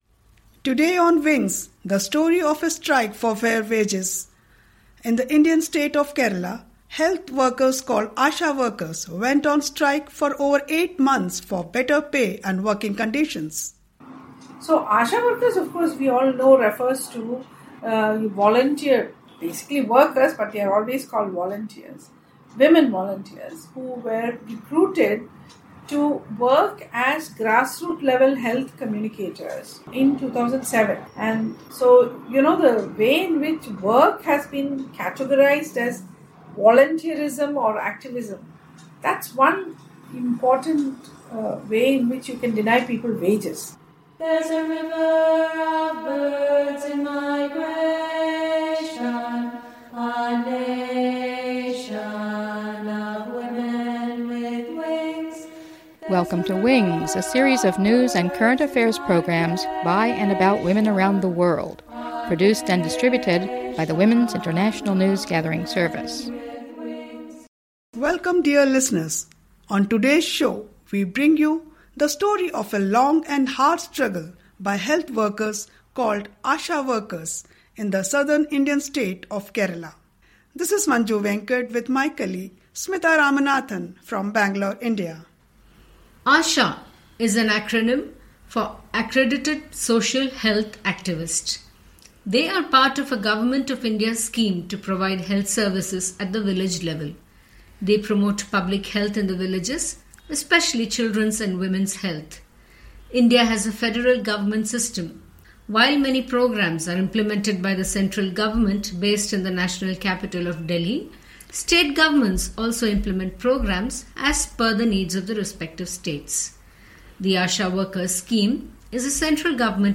reads English translation.